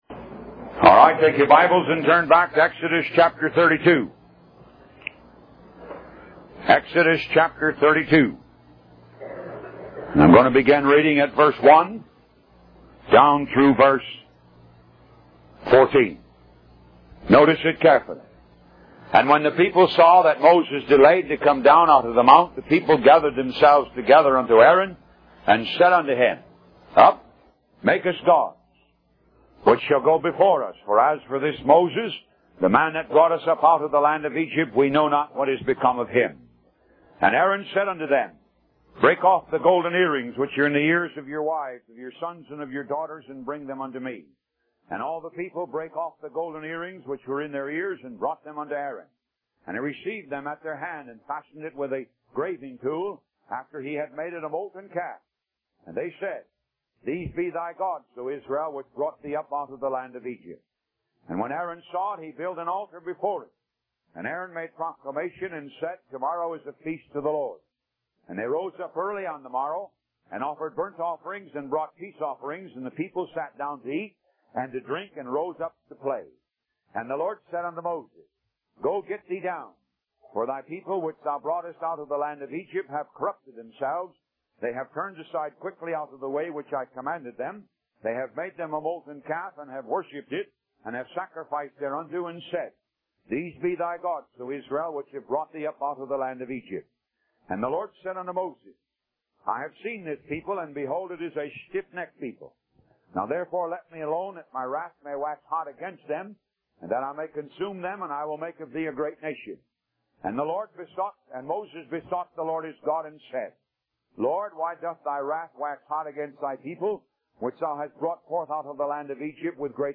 Talk Show Episode, Audio Podcast, Moga - Mercies Of God Association and The Dilemma Of The Church on , show guests , about The Dilemma Of The Church, categorized as Health & Lifestyle,History,Love & Relationships,Philosophy,Psychology,Christianity,Inspirational,Motivational,Society and Culture